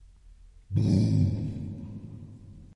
描述：游戏/电影的悬念主题。可能适用于涉及黑暗事物的场景。
标签： 钢琴 音乐 电影 悬疑 游戏 黑暗 声音 主题 电影 神秘
声道立体声